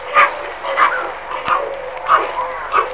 Krauskopfpelikan (Pelecanus crispus)
Stimme: Knurrend-blökende Laute sowie Klappern.
Pelecanus.crispus.wav